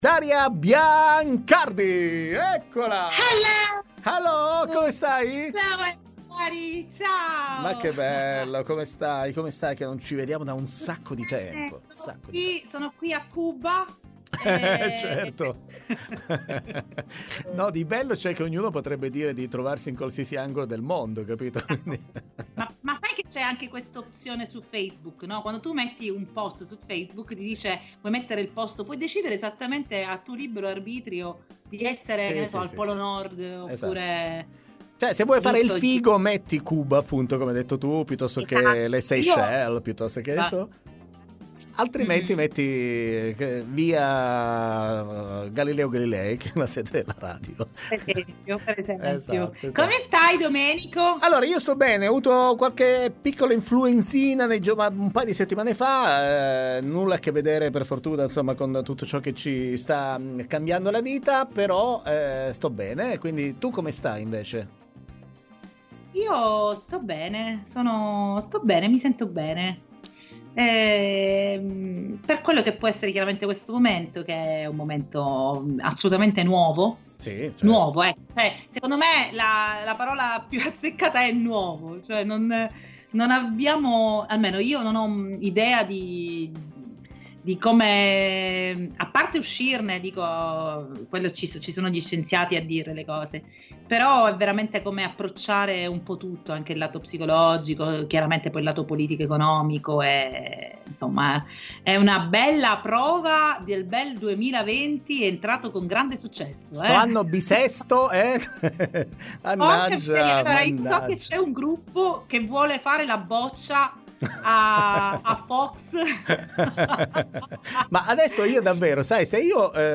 Doc Time intervista